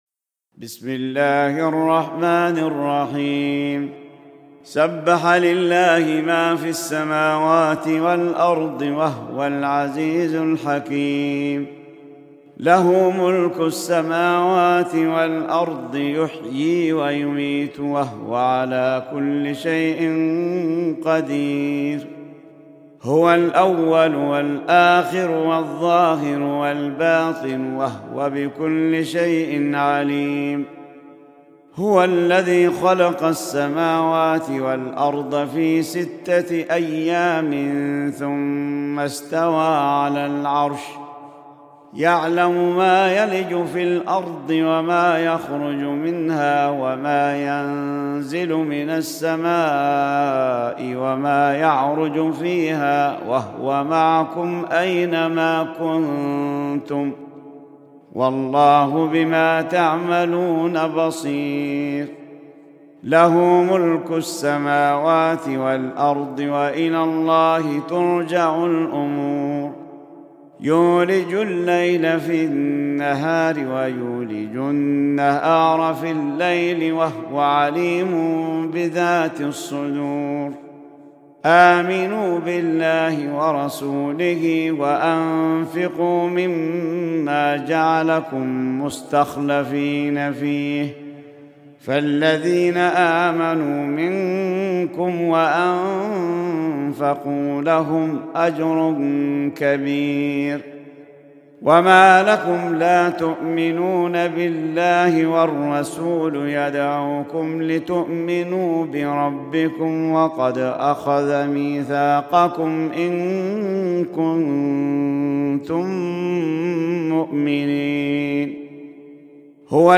Sûrat Al-Hadid (The Iron) - Al-Mus'haf Al-Murattal (Narrated by Qaaloon from Naafi')